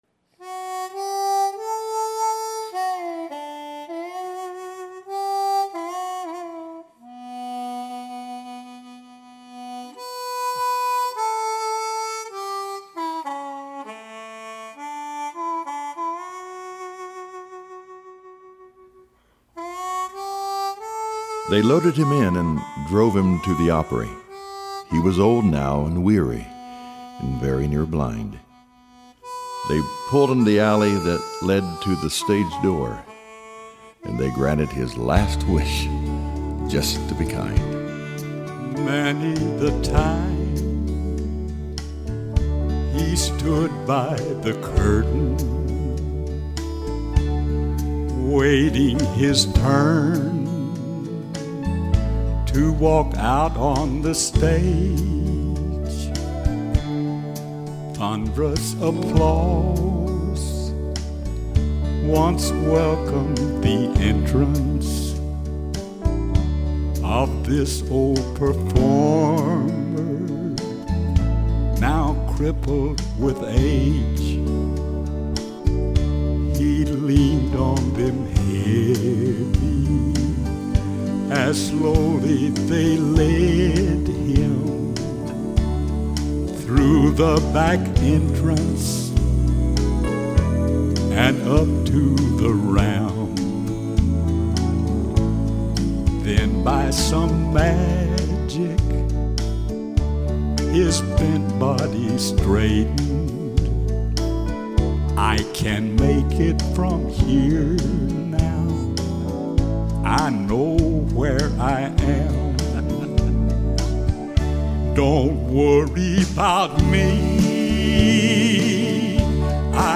Esta história levou o casal a compor uma canção gospel